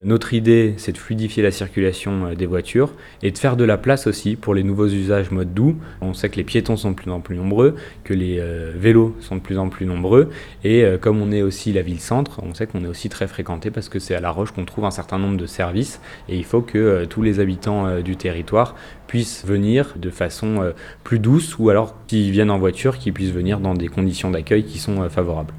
Une expérimentation qui concerne notamment les secteurs du centre-ville et de la Gare ainsi que certaines liaisons du quartier Broÿs. Pierrick Ducimetière le maire de La-Roche-sur-Foron explique pourquoi.